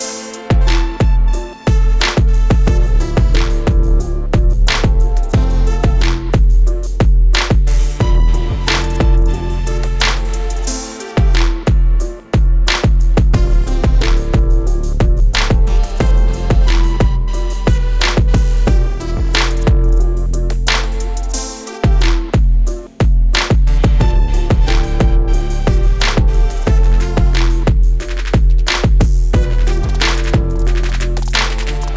Aggressive (Pink)
A Minor